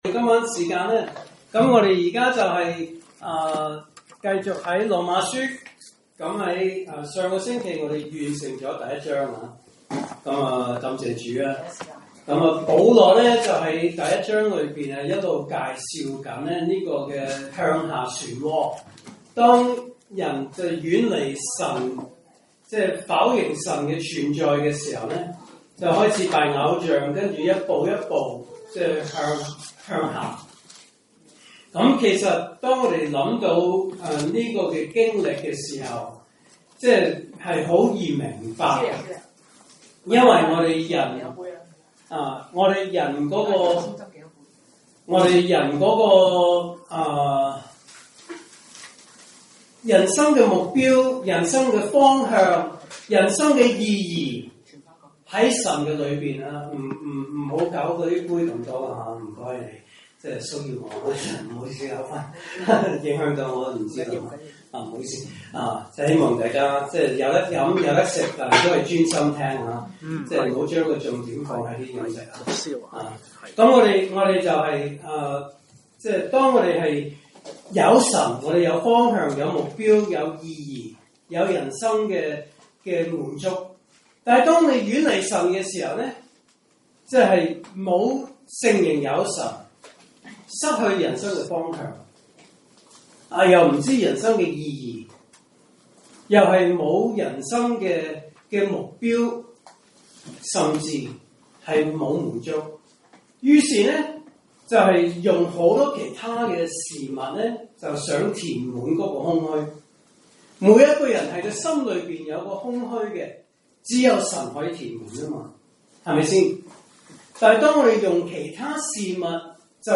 證道信息: “羅馬書 2:1-4